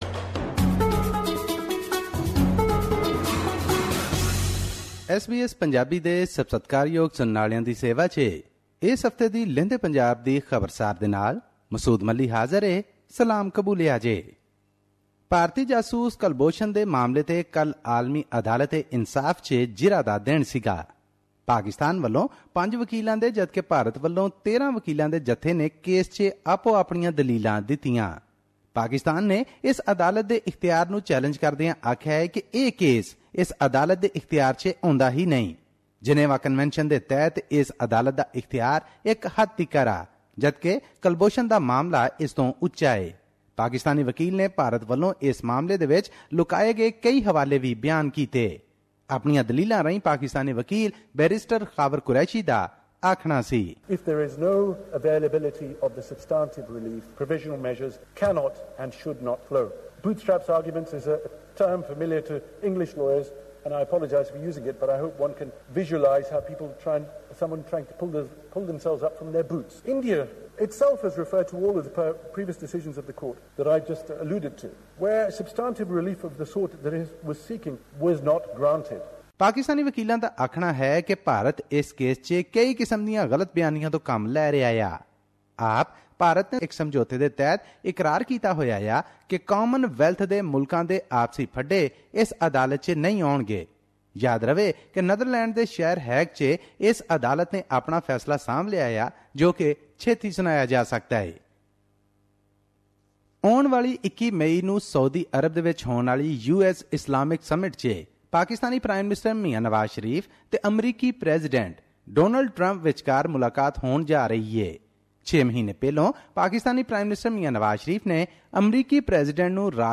His report was presented on SBS Punjabi program on Tuesday, May 16 2017, which touched upon issues of Punjabi and national significance in Pakistan.